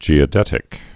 (jēə-dĕtĭk) also ge·o·det·i·cal (-ĭ-kəl)